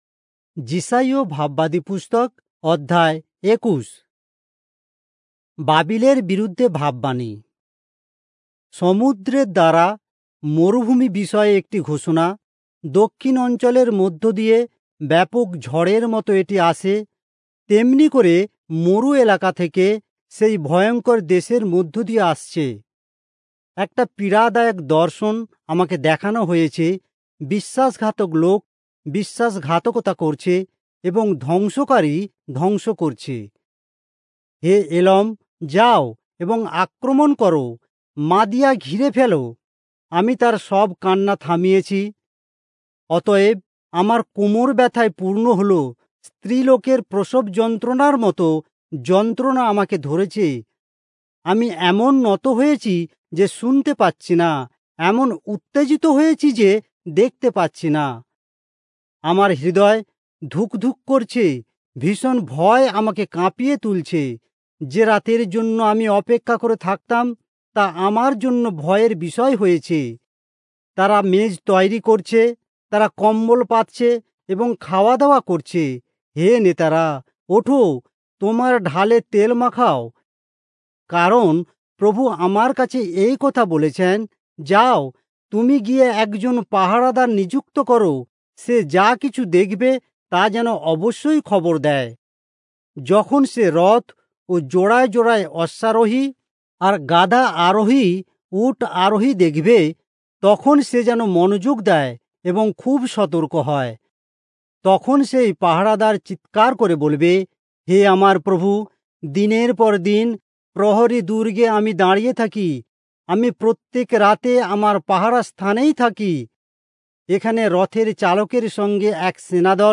Bengali Audio Bible - Isaiah 18 in Irvbn bible version